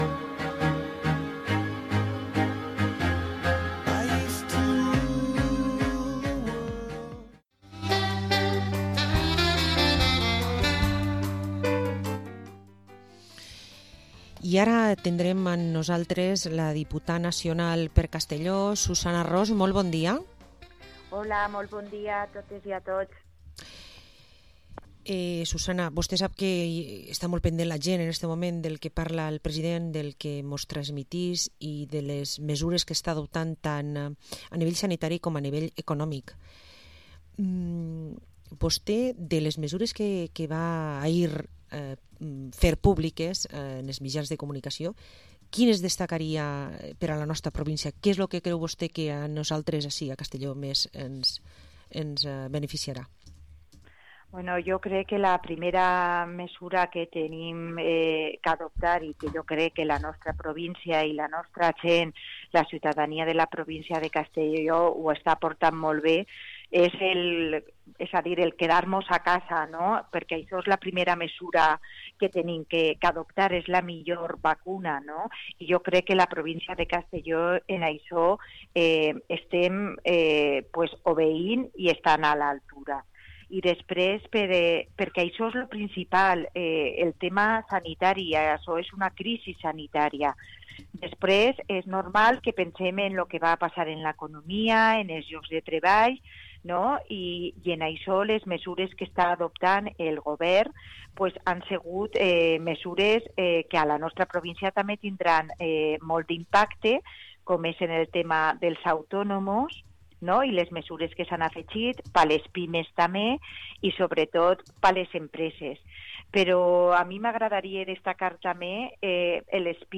Entrevista a Susana Ros, diputada nacional pel PSPV-PSOE